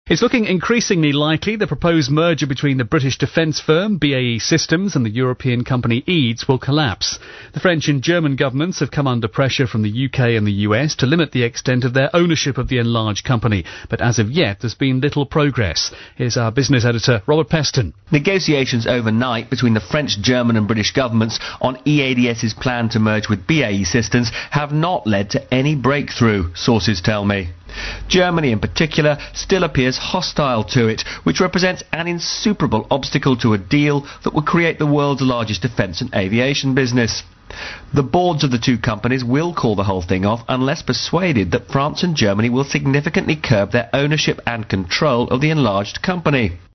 Voicer example